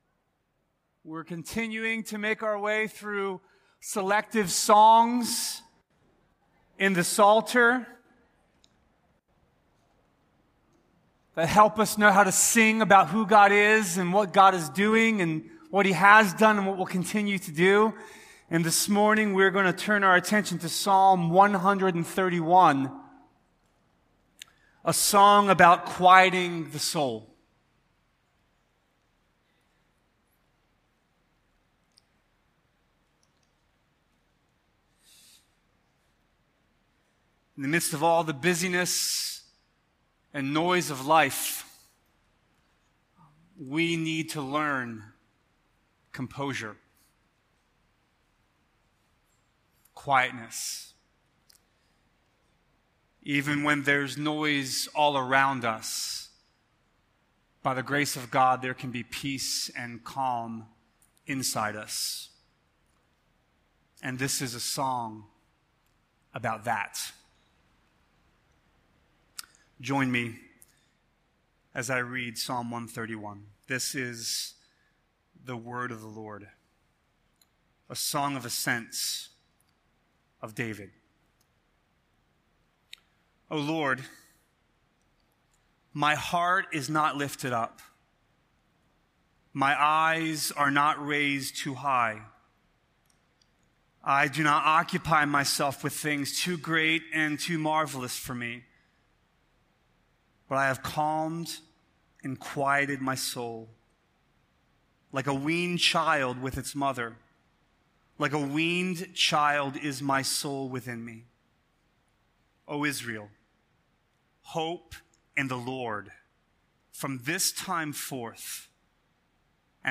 A sermon from the series "The Psalms." Psalm 130 is written out of the depths, where we can find ourselves believing that we're alone.